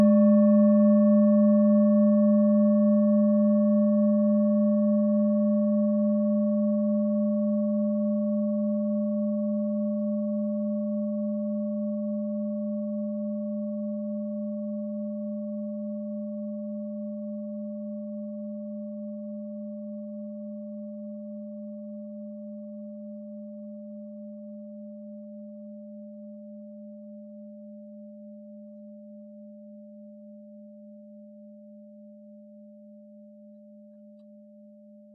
Klangschale Orissa Nr.30
Klangschale-Durchmesser: 17,4cm
Die Klangschale kommt aus einer Schmiede in Orissa (Nordindien). Sie ist neu und wurde gezielt nach altem 7-Metalle-Rezept in Handarbeit gezogen und gehämmert.
Der Uranuston liegt bei 207,36 Hz und ist die 39. Oktave der Umlauffrequenz des Uranus um die Sonne. Er liegt innerhalb unserer Tonleiter nahe beim "Gis".